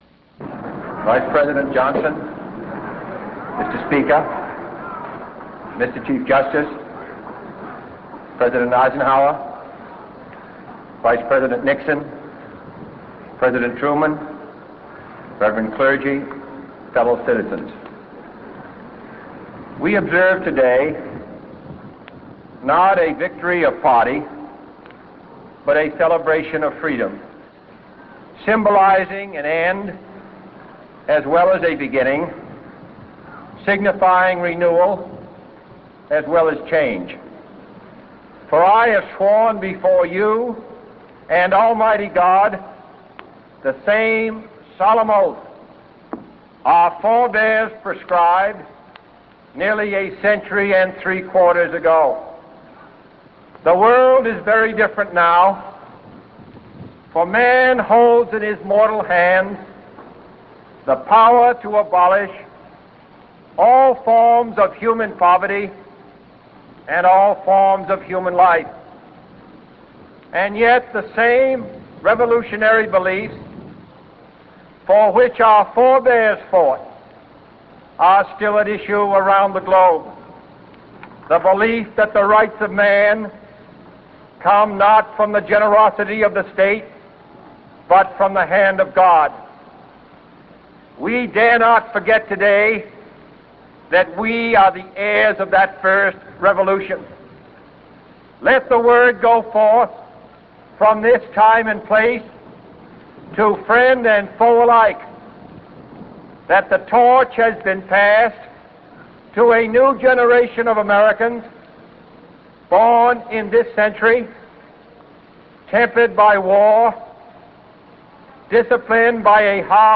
J.F. Kennedy, Inaugural Address, 1961
10_jfk_inaugural_address.rm